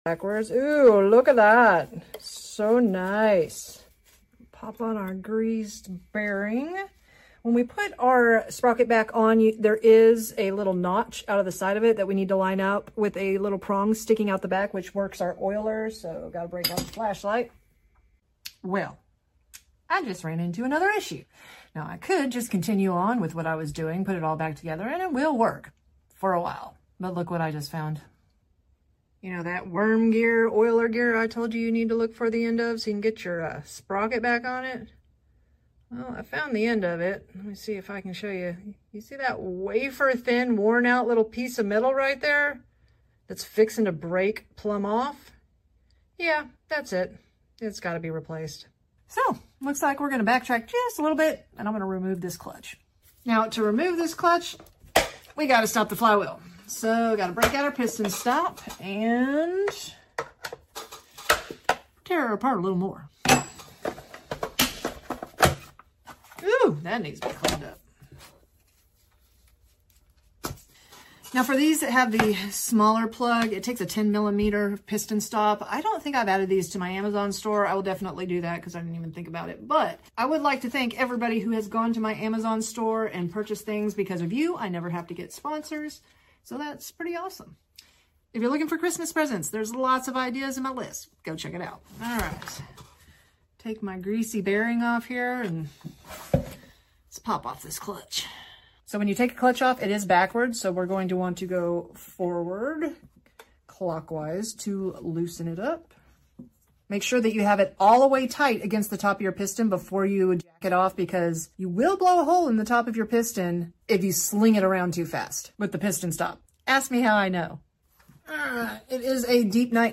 Stihl MS 462 Making Terrible Sound!